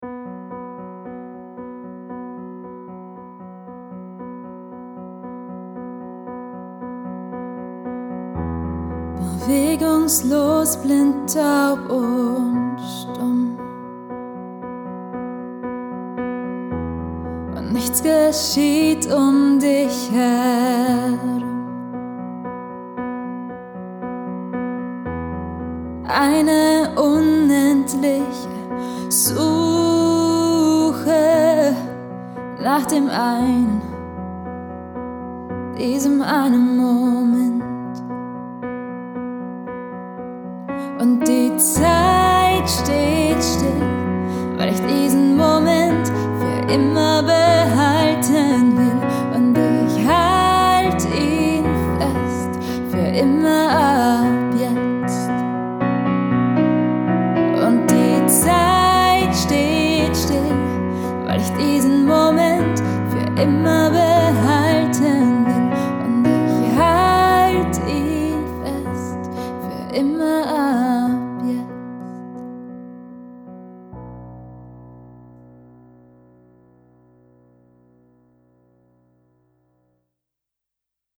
Duo